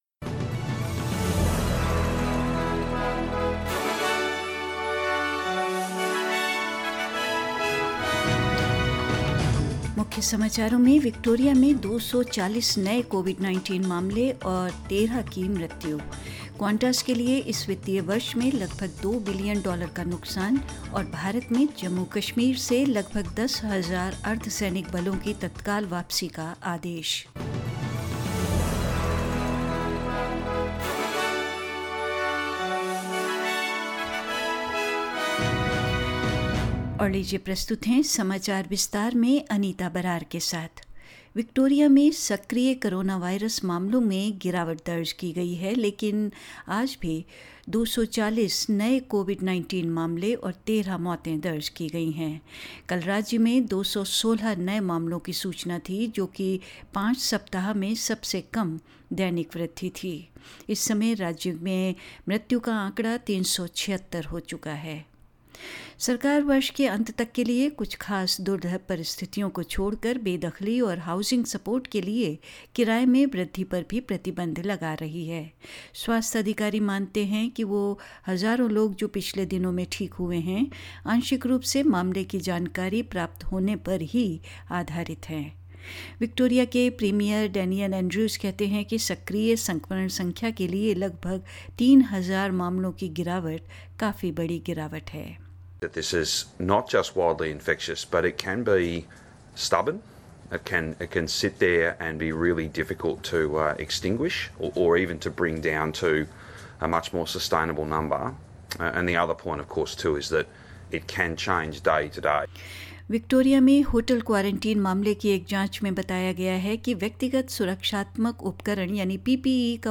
News in Hindi 20th August 2020